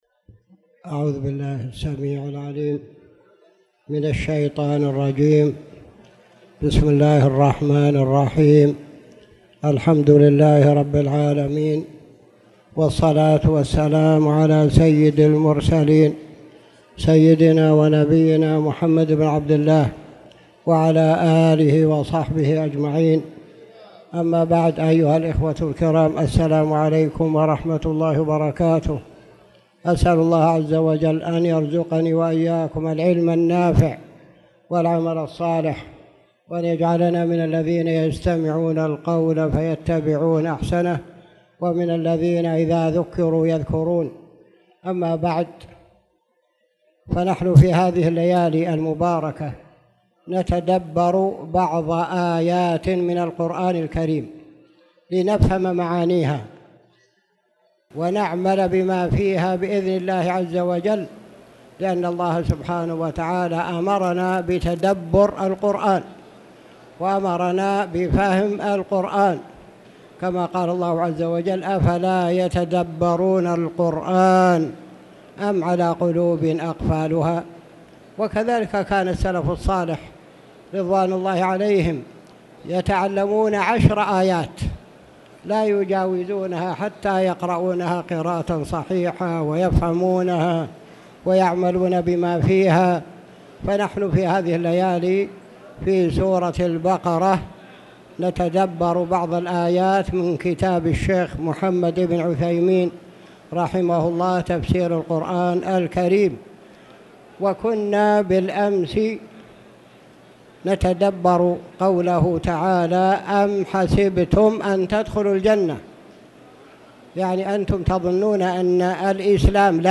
تاريخ النشر ٨ رمضان ١٤٣٧ هـ المكان: المسجد الحرام الشيخ